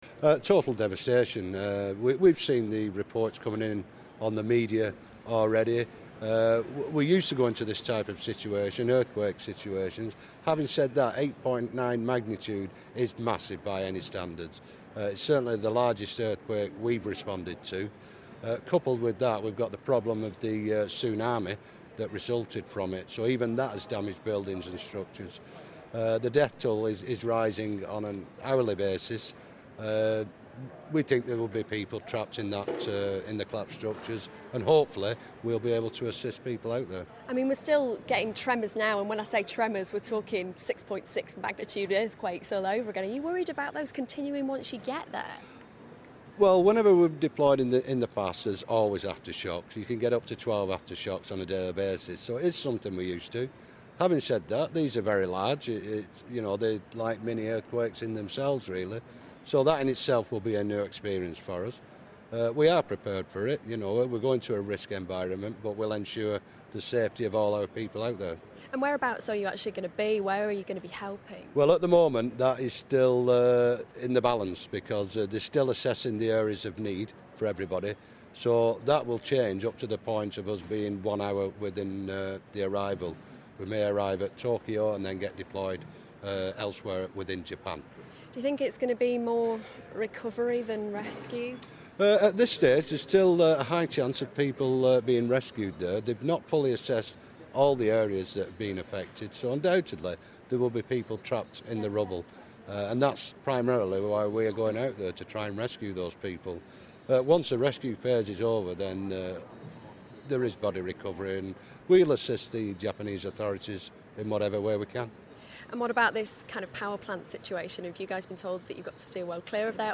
from Manchester airport as they head to Japan